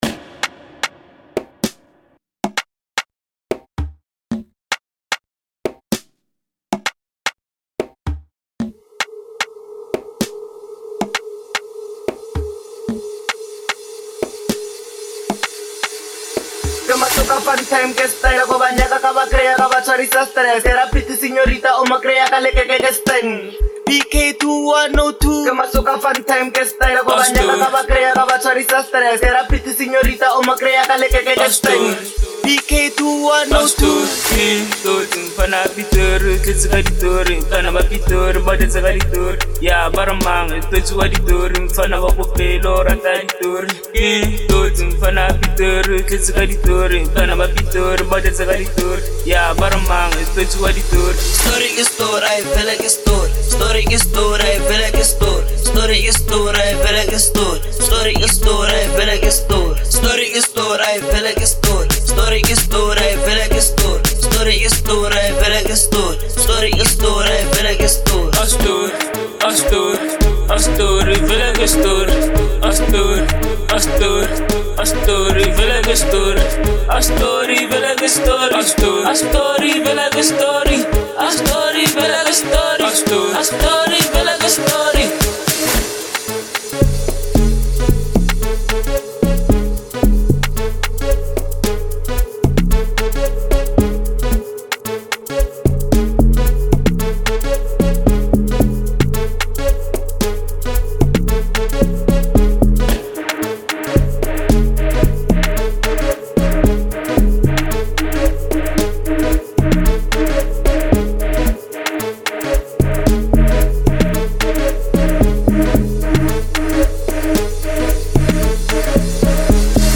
05:34 Genre : Amapiano Size